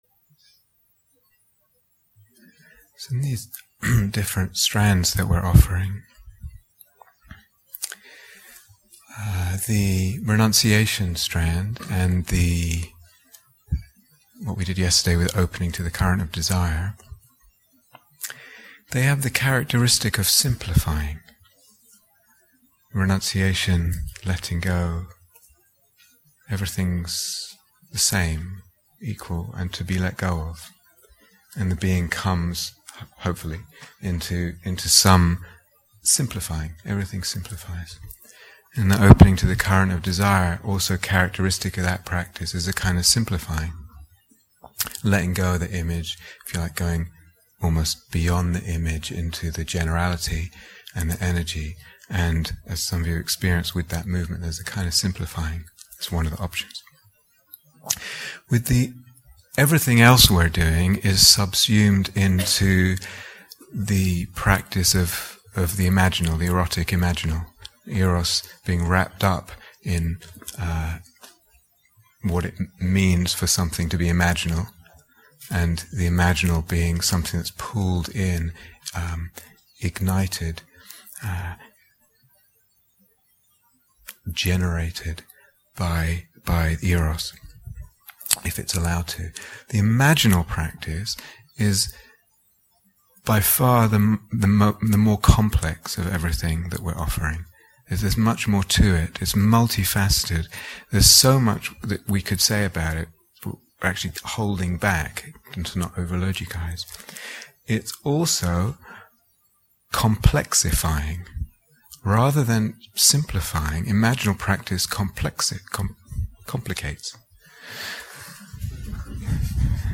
Intention and Devotion (Guided Meditation)